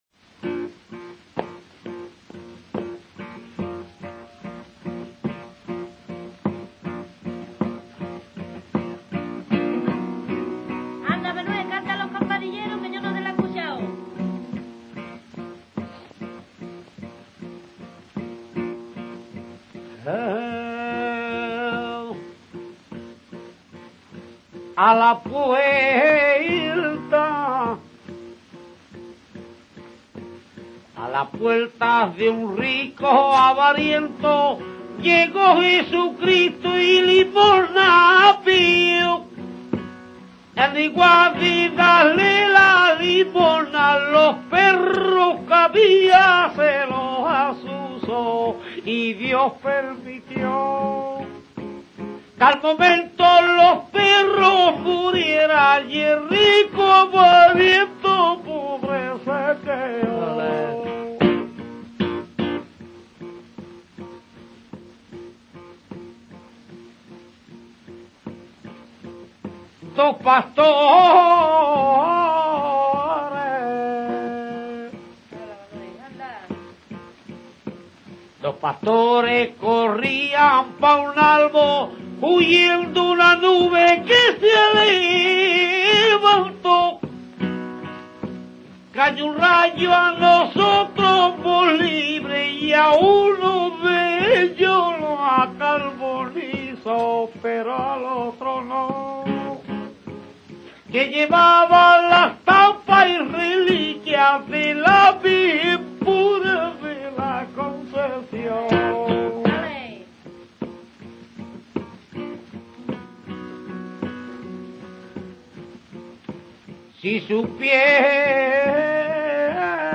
Escuchar unos Campanilleros
campanilleros.mp3